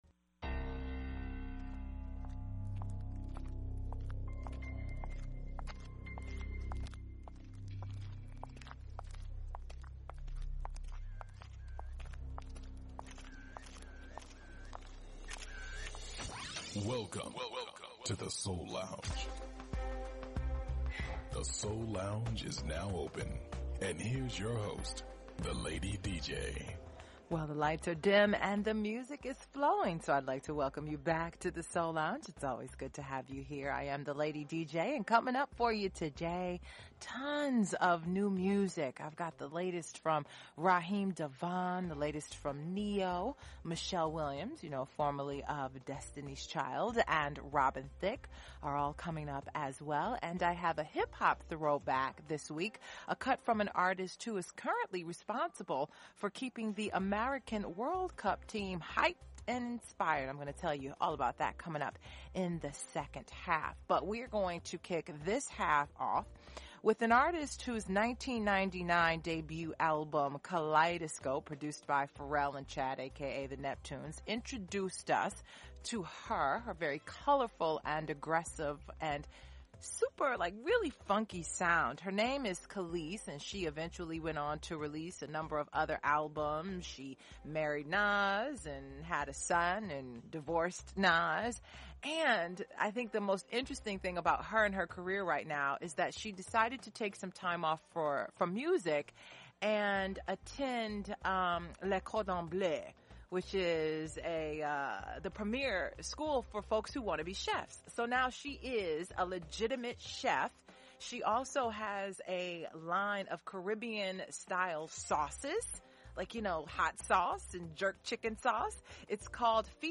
music, interviews and performances
conscious Hip-Hop
Classic Soul